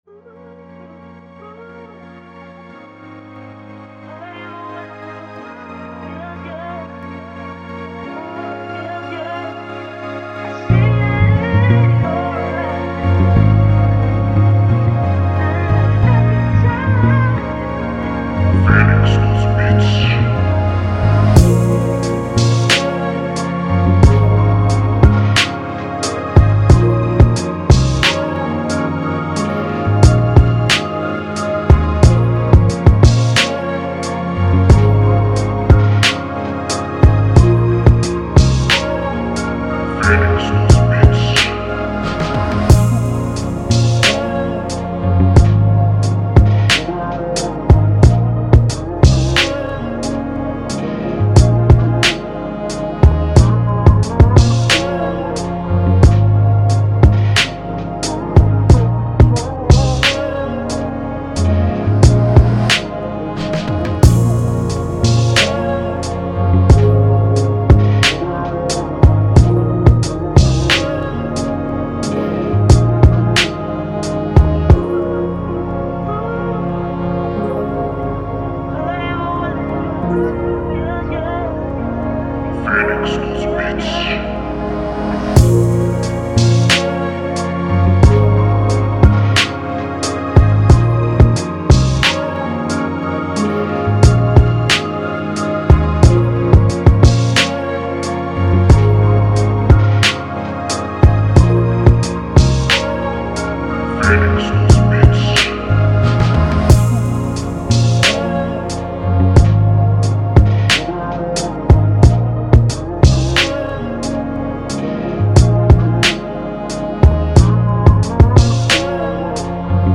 Sad Emotional RNB Instrumental